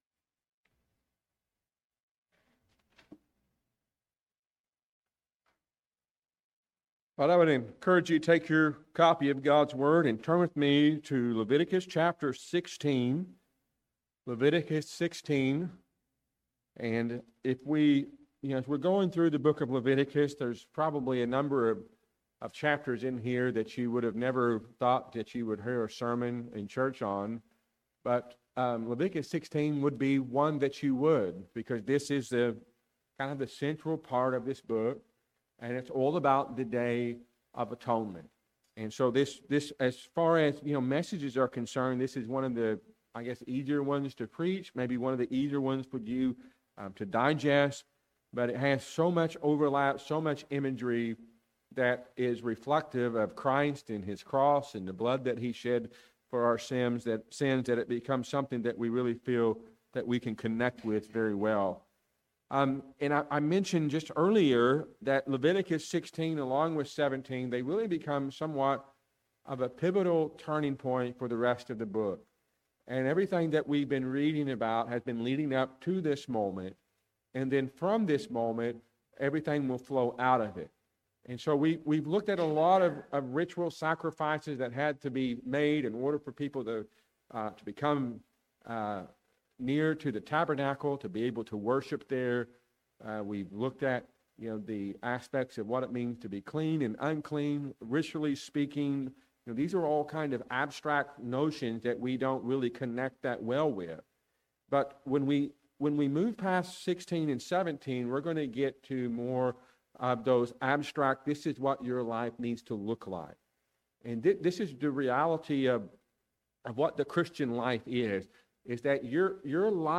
A sermon from Leviticus 16.